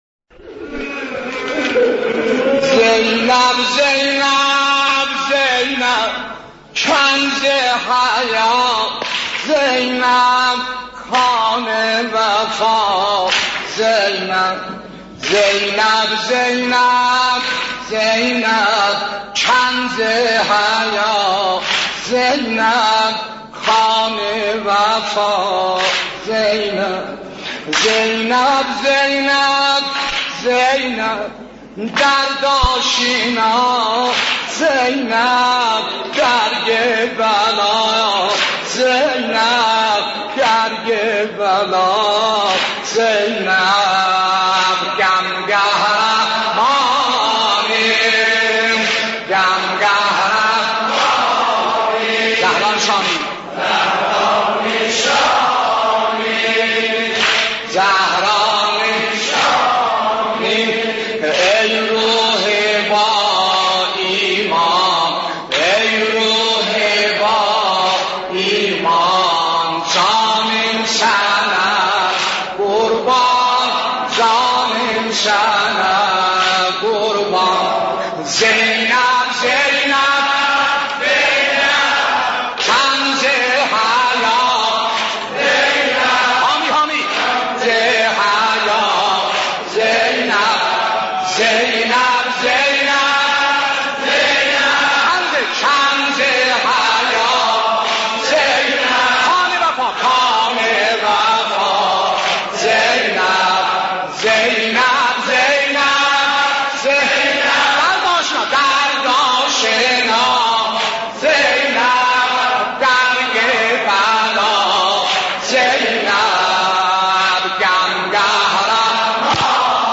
نوحه زیبا